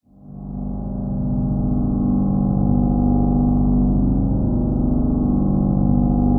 HORROR MUSIC BOX / Loop
A Broken Toy_Drone Sound.wav